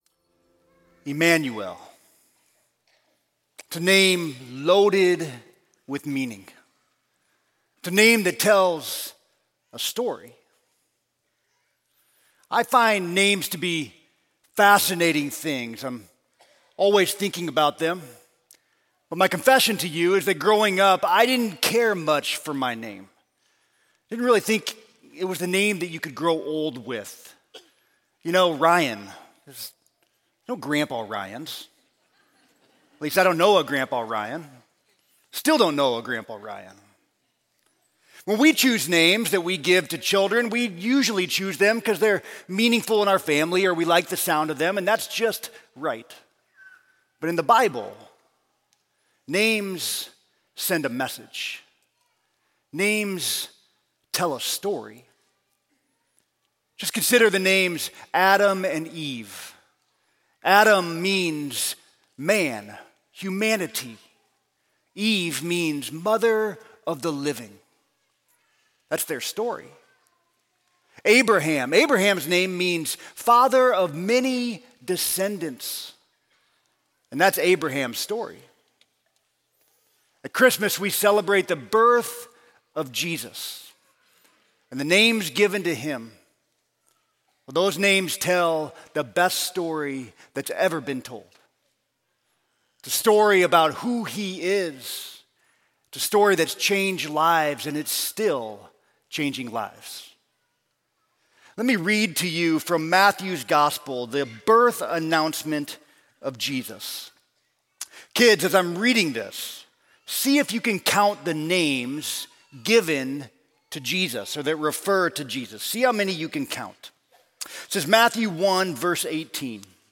Sermon: God with Us Through Christ